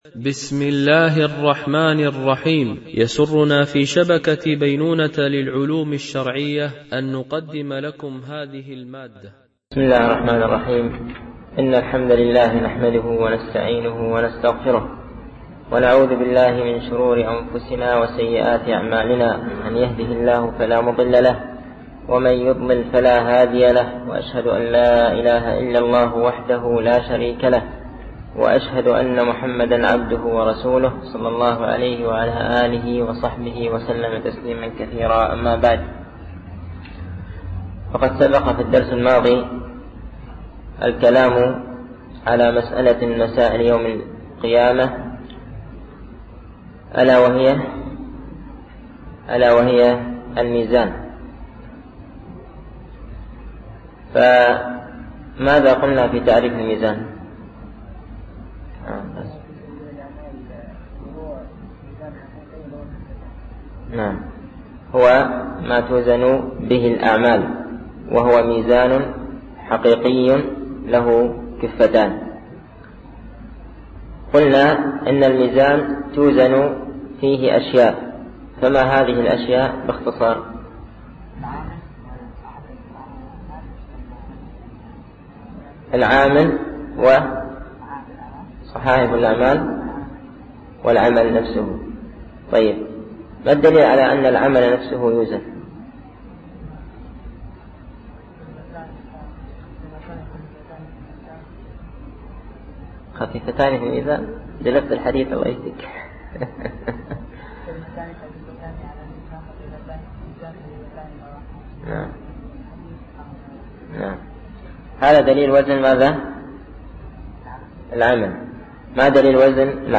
شرح أعلام السنة المنشورة ـ الدرس 47 ( ما دليل الصراط من الكتاب ؟ ما دليل ذلك وصفته من السنة ؟ )